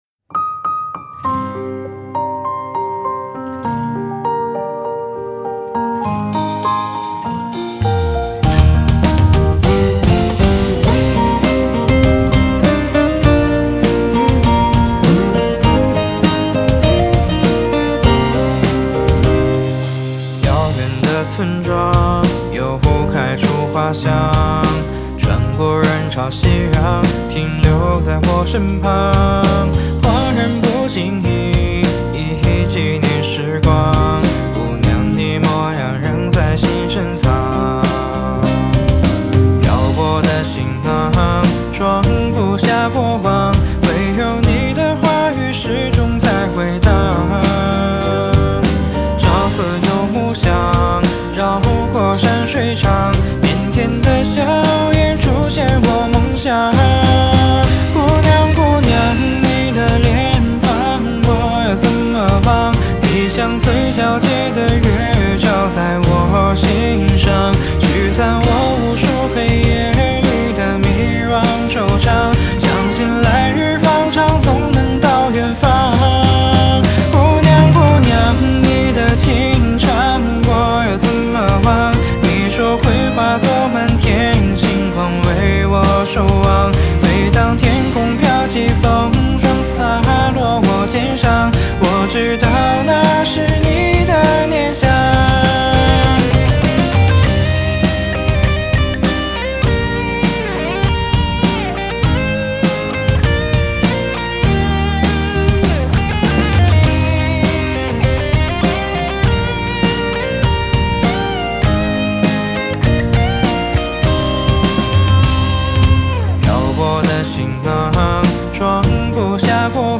谱内音轨：架子鼓